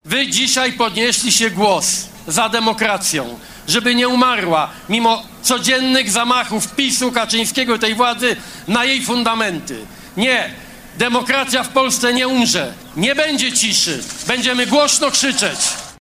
Pokazaliśmy, że nie damy się podzielić ani zniszczyć – mówił Donald Tusk w swoim wystąpieniu na placu Zamkowym w Warszawie. Wystąpienie szefa PO zakończyło wielki Marsz 4 czerwca.